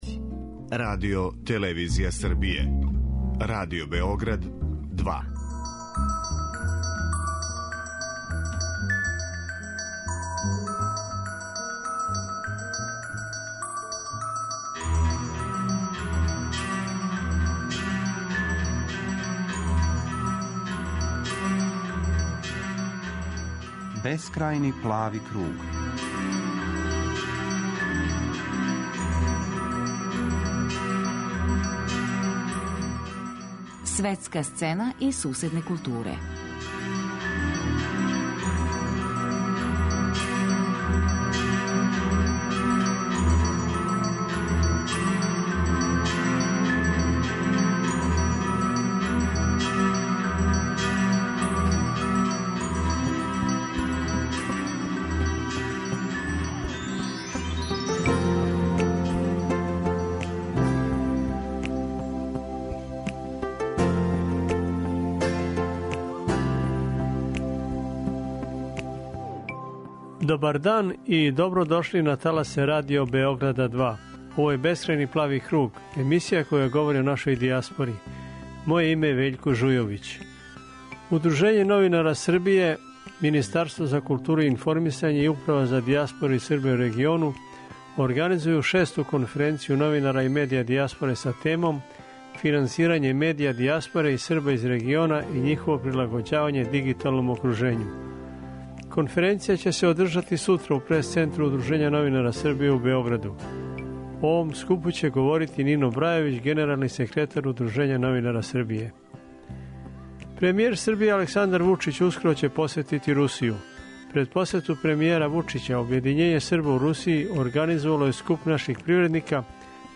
Чућемо део његовог обраћања присутнима на отварању изложбе, која ће трајати до 20. новембра.